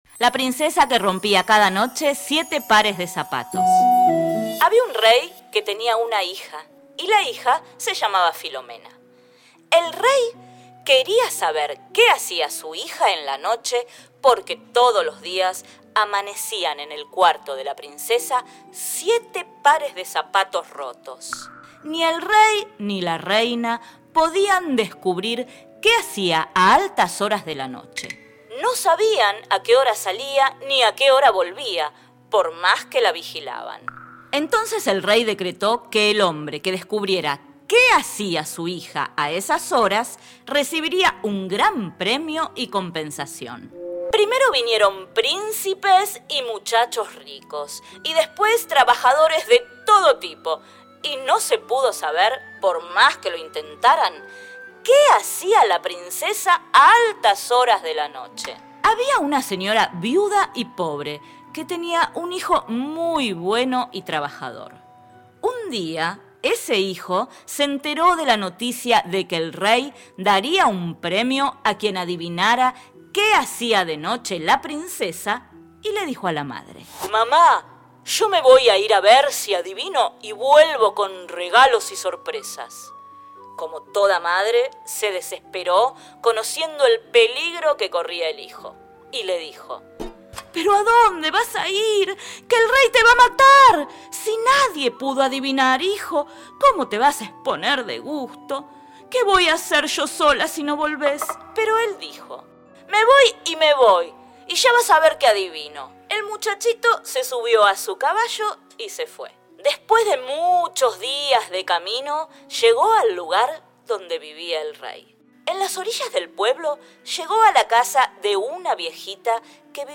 Download: los 7 zapatos de la princesa FINAL.mp3 Hoy te presentamos este relato de la narración oral argentina en donde te contamos qué secreto oculta la princesa y cual es el misterio de los siete pares de zapatos.